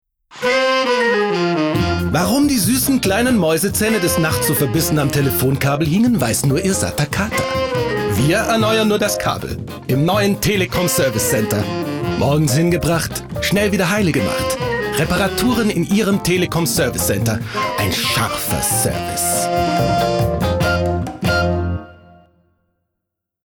deutscher Sprecher und Schauspieler.
Sprechprobe: Werbung (Muttersprache):